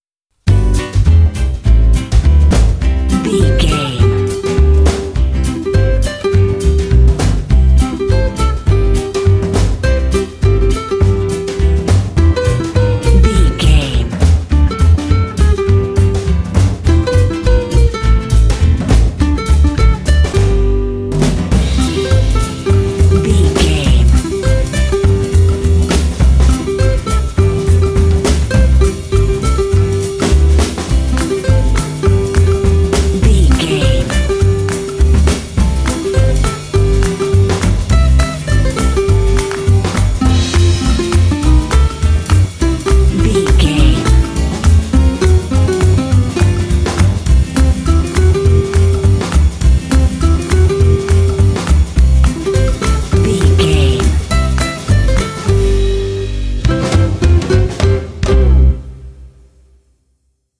Ionian/Major
playful
uplifting
calm
cheerful/happy
drums
acoustic guitar
bass guitar